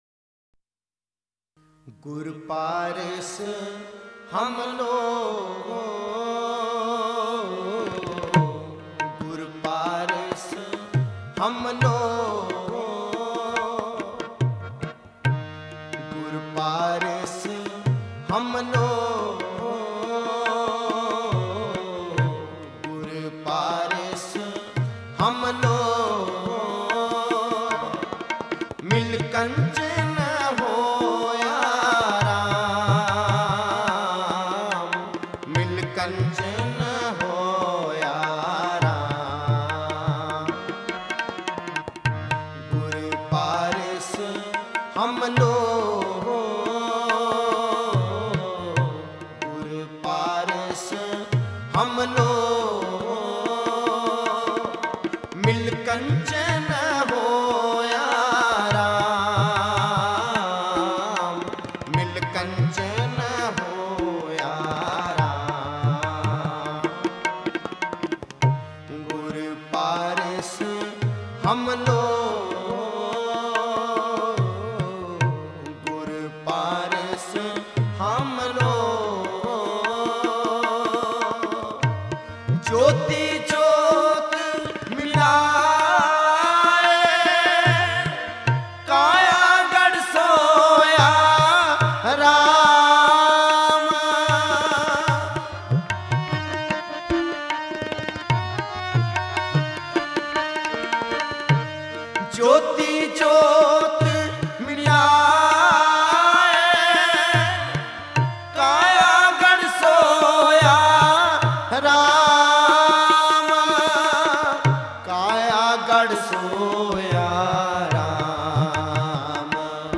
Genre: Shabad Gurbani Kirtan